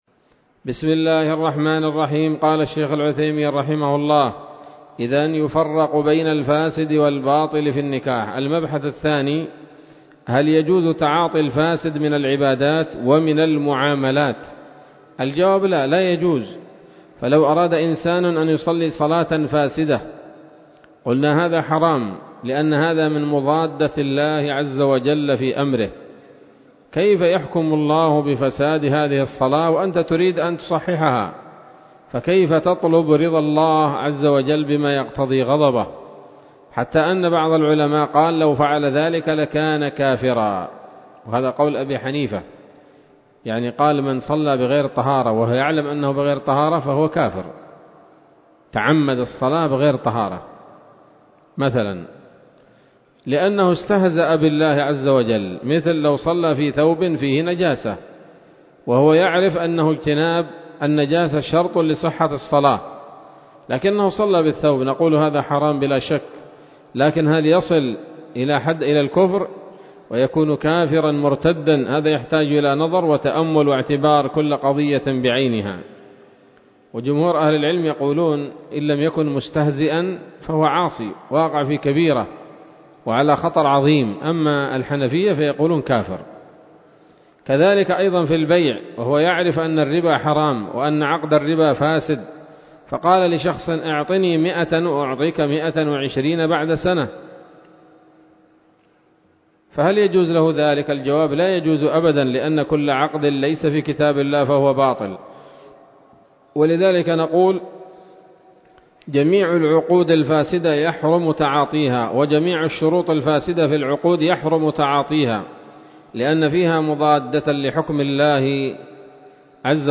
الدرس التاسع عشر من شرح نظم الورقات للعلامة العثيمين رحمه الله تعالى